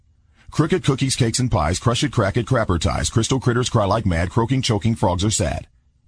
tongue_twister_03_02.mp3